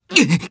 Fluss-Vox_Damage_jp_02.wav